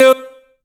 BWB VAULT VOX (Edm Pop).wav